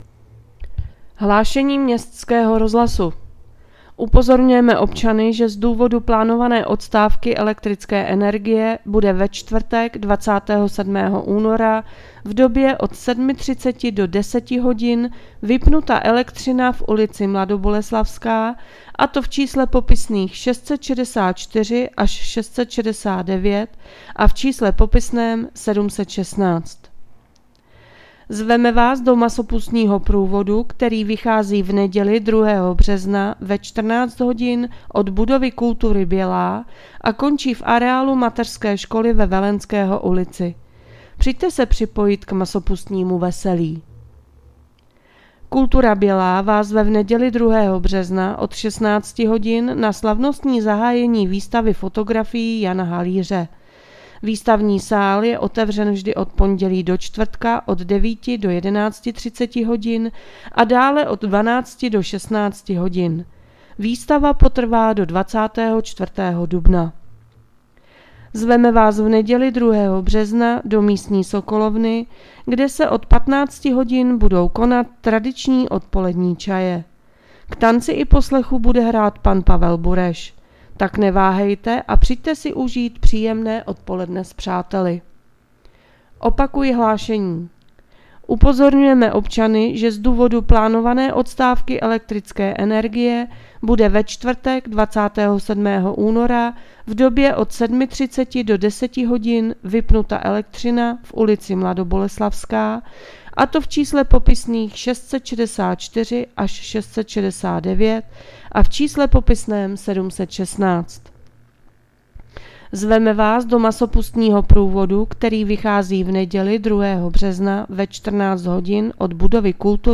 Hlášení městského rozhlasu 26.2.2025